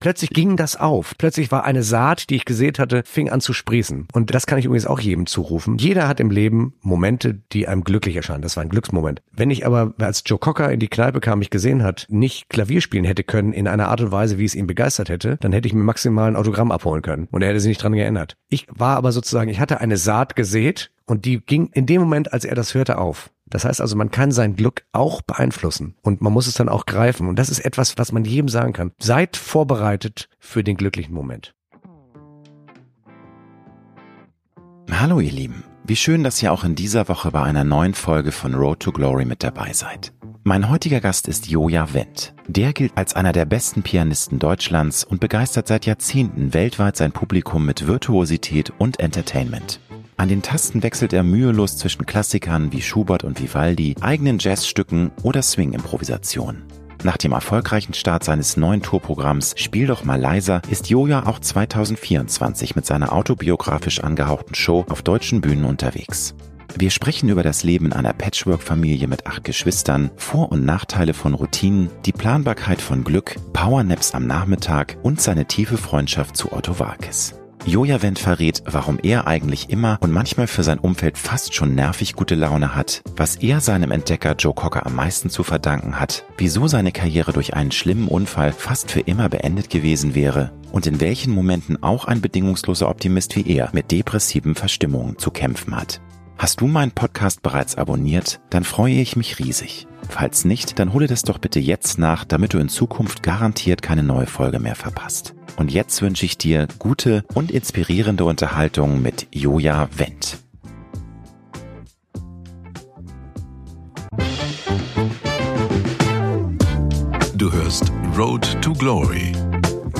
Mein heutiger Gast ist Joja Wendt: Der gilt als einer der besten Pianisten Deutschlands und begeistert seit Jahrzehnten weltweit sein Publikum mit Virtuosität und Entertainment.